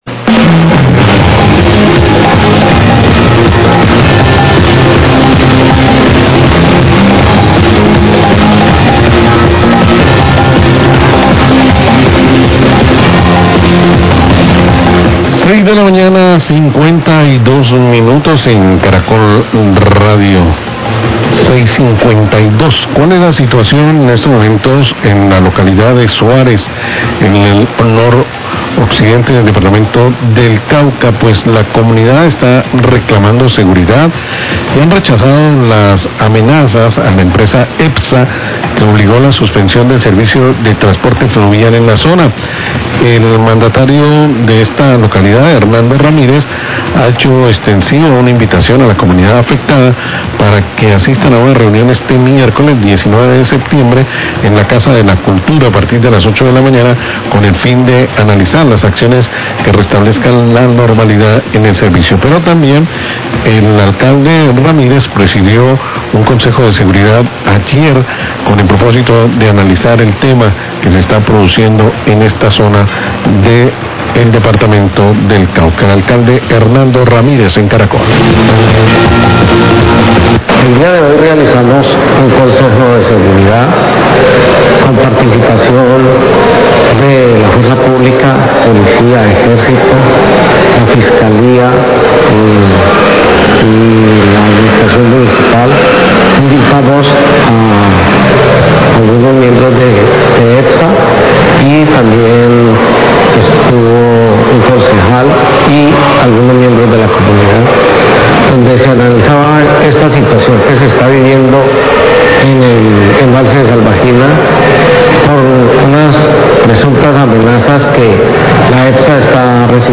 Radio
Declaraciones del Alcalde de Suárez, Hernando Ramirez.